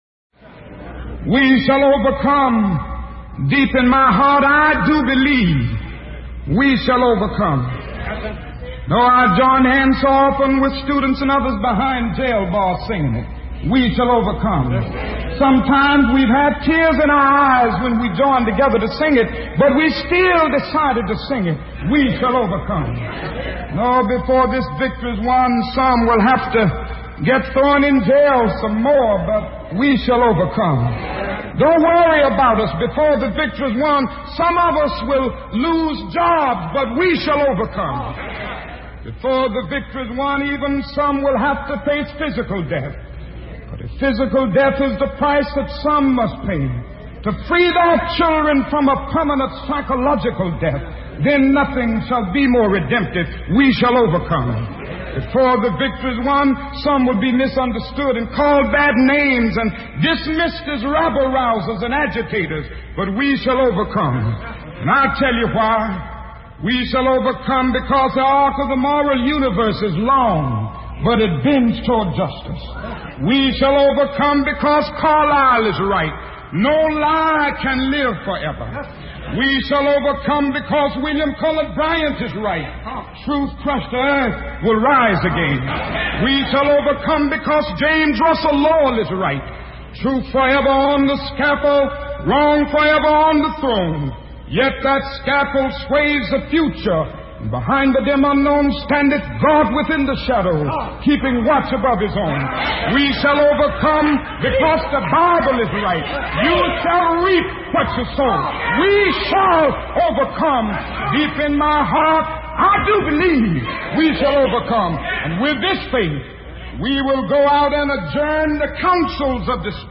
Мне бы очень хотелось, чтобы вы теперь прослушали фрагмент из выступления (или проповеди?) Мартина Лютера Кинга, которое прекрасно передаёт атмосферу тех бурных 60-х годов. Перевод едва ли нужен — «we shall overcome» звучит там буквально в каждой фразе. Как и в одноимённой песне, энергетика этого страстного выступления важнее конкретного смысла произносимых там слов.